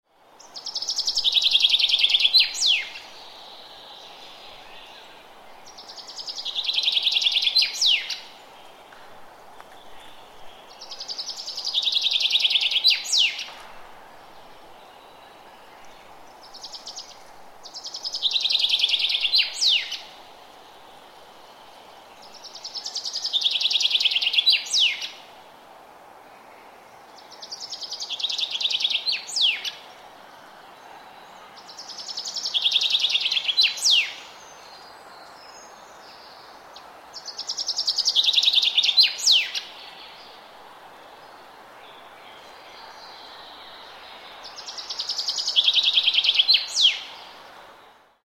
Morning Birds Sound Effect
Forest-bird-chirping-sound.mp3